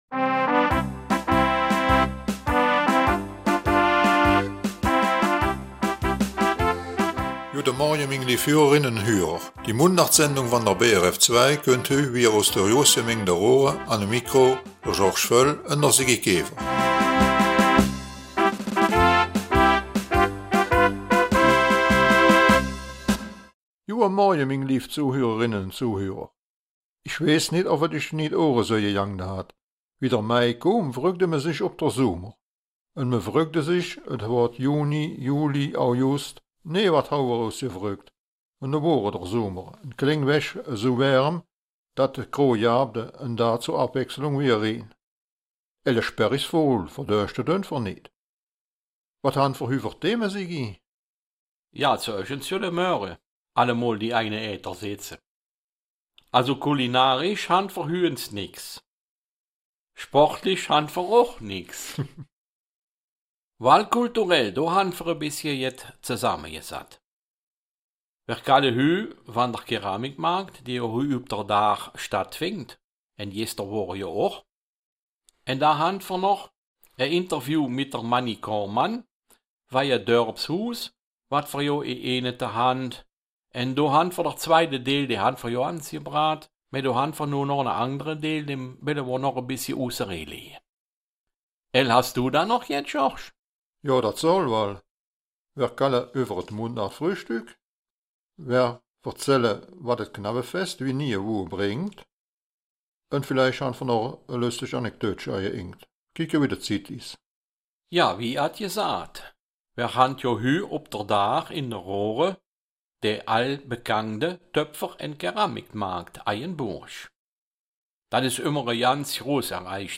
Raerener Mundart - 14. September